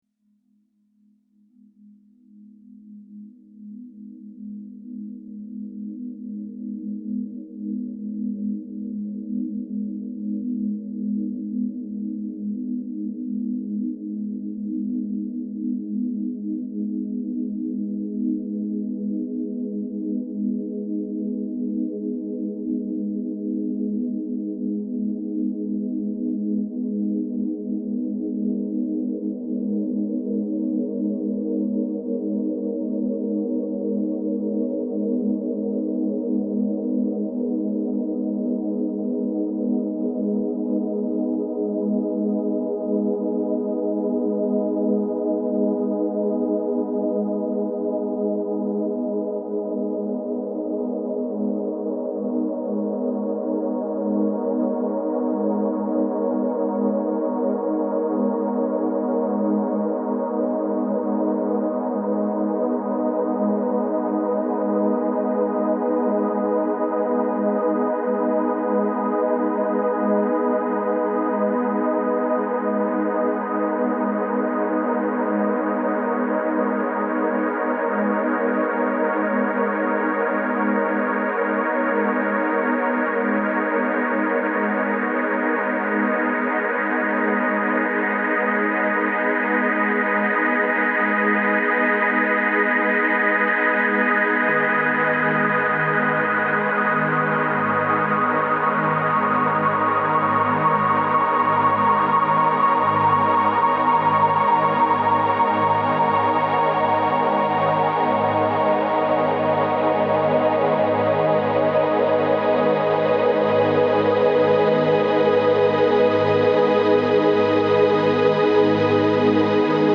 Deep-Space-Ambient-Sound.mp3